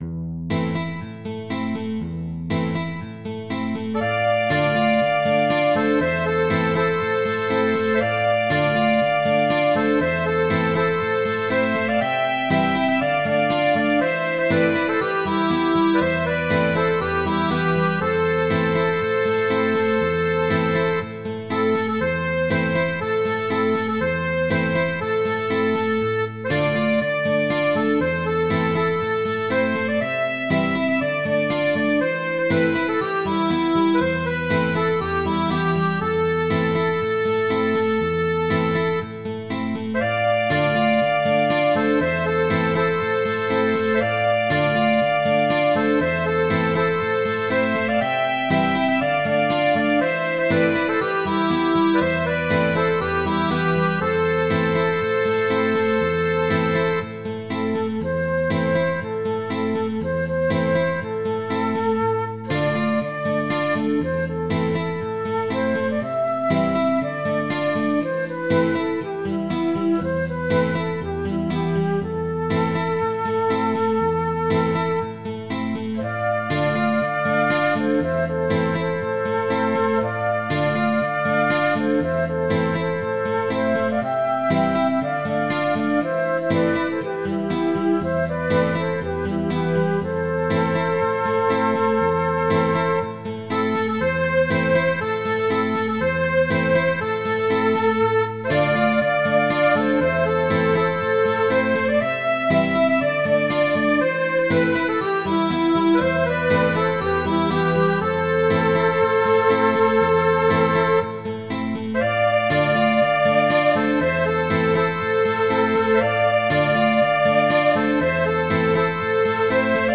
Midi File, Lyrics and Information to The Drinking Gourd